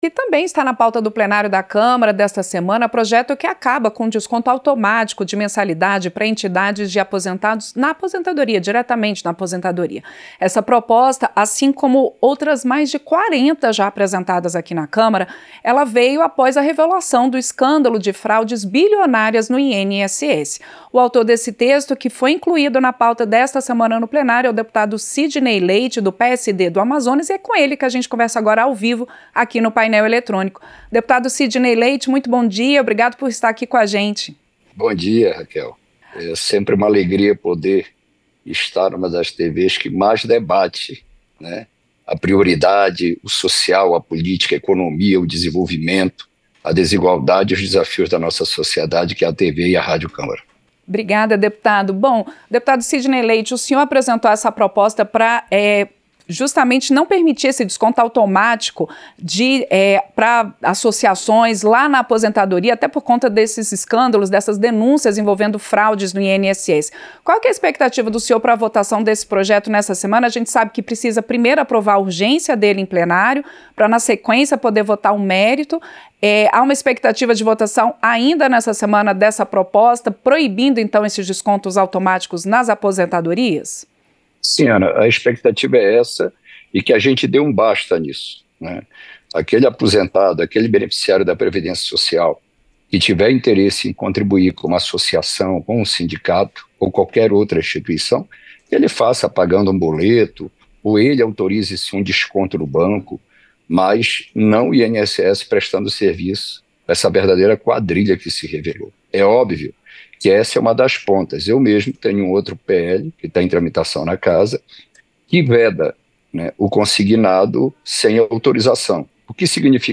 • Entrevista - Dep. Sidney Leite (PSD-AM)
Programa ao vivo com reportagens, entrevistas sobre temas relacionados à Câmara dos Deputados, e o que vai ser destaque durante a semana.